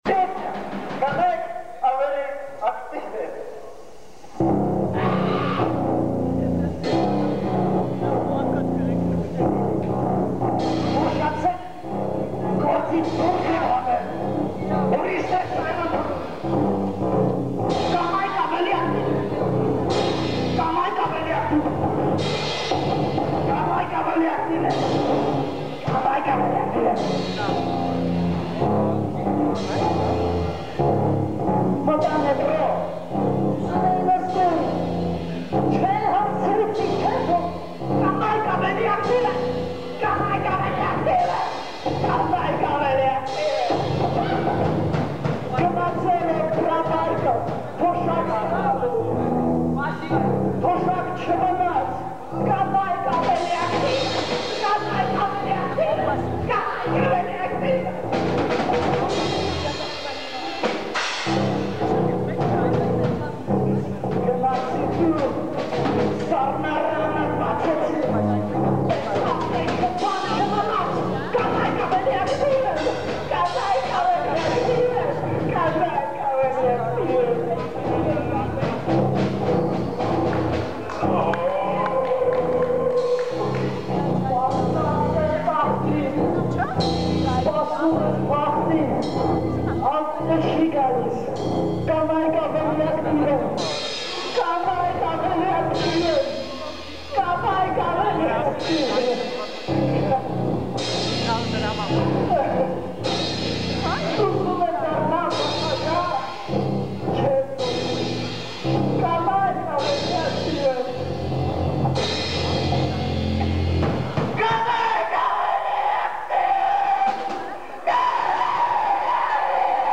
КОНЦЕРТ В КИНОТЕАТРЕ "ПИОНЕР"
синтезатор
барабанная установка